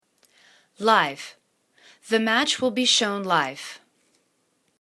live    /li:v/    adv